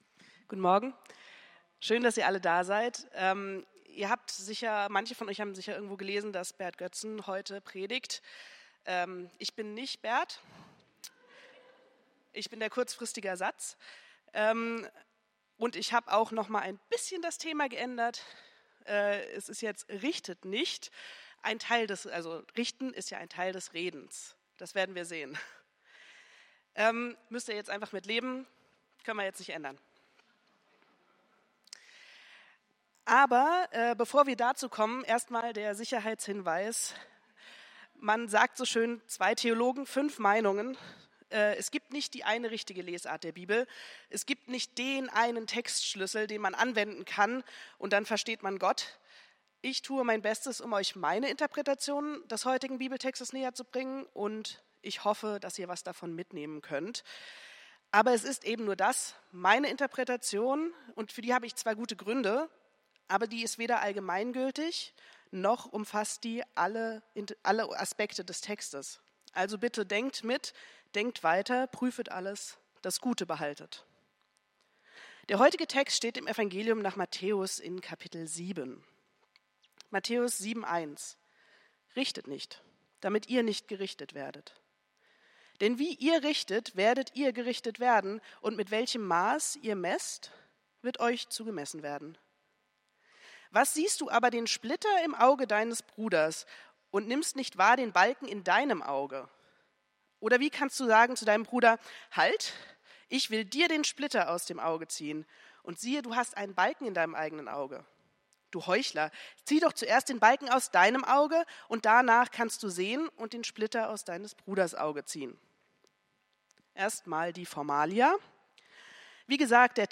Predigt vom 04.02.2024